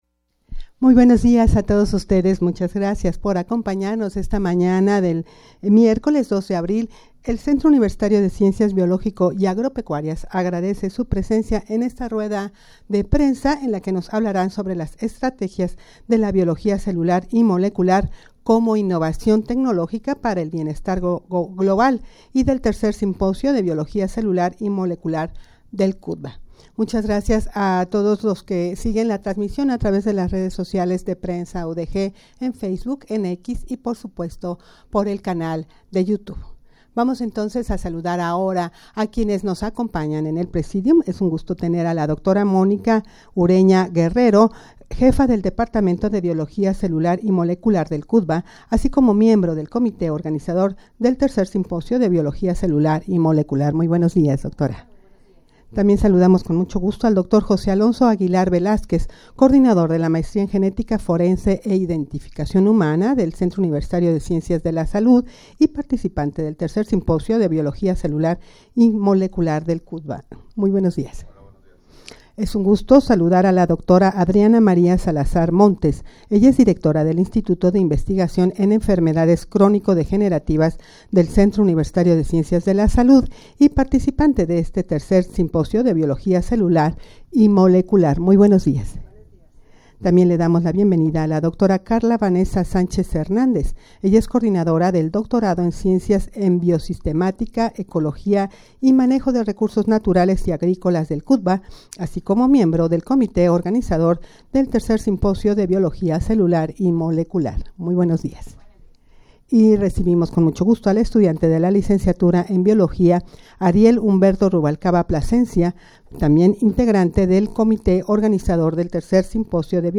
rueda-de-prensa-estrategias-de-biologia-celular-y-molecular-como-innovacion-tecnologica-para-el-bienestar-global.mp3